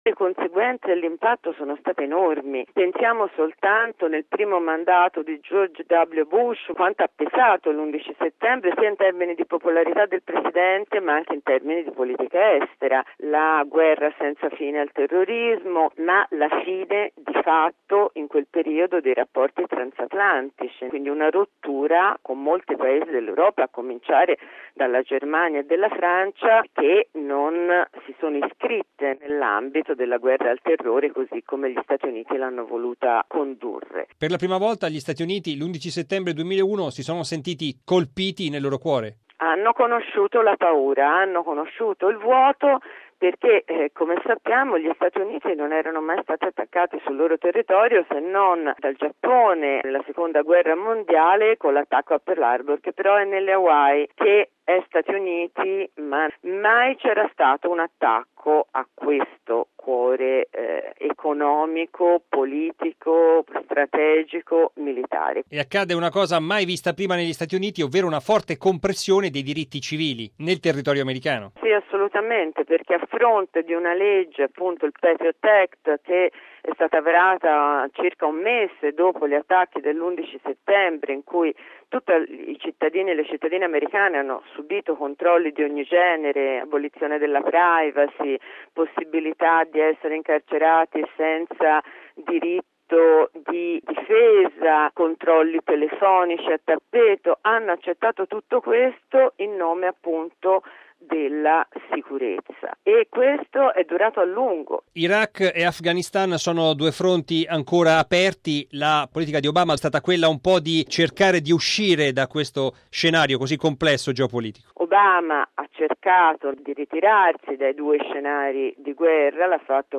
Radiogiornale del 11/09/2012 - Radio Vaticana